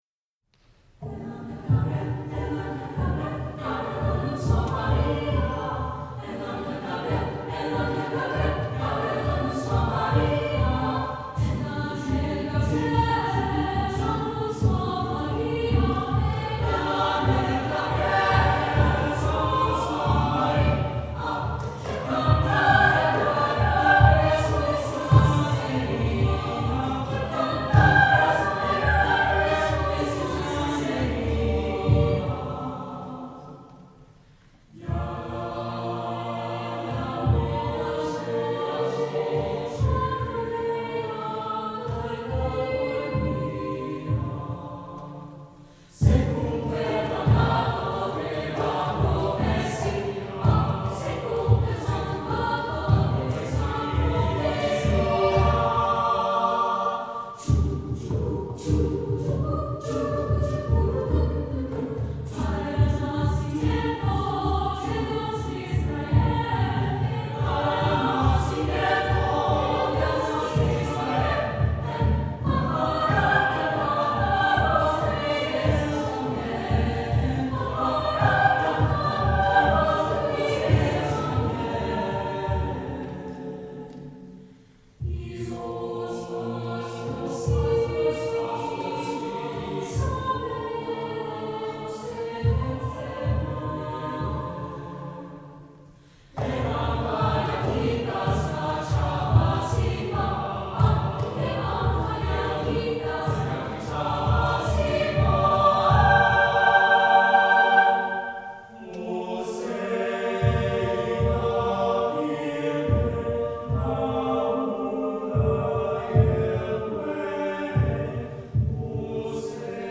SATB a cappella + opt. Percussion. 3’20”
This arrangement suggest playing the rhythm on a cajón.
SATB, Cajón (Optional), Piano Reduction